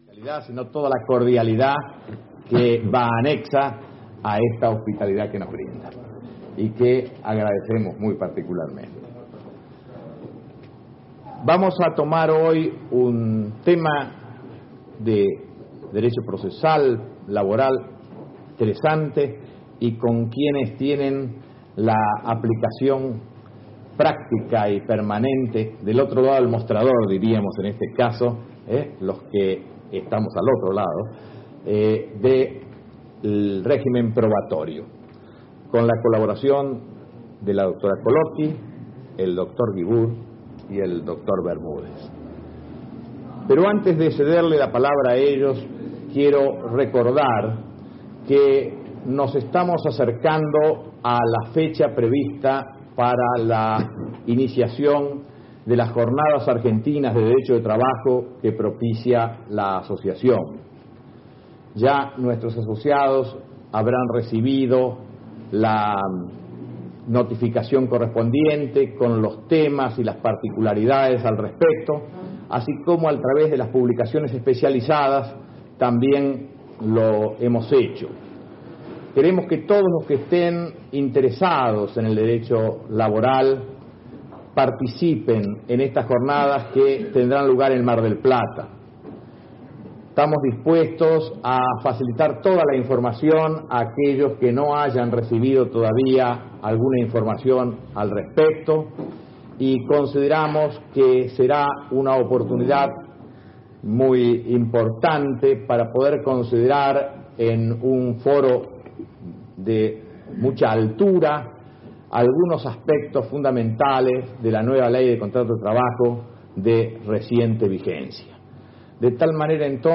Exposiciones de los Dres.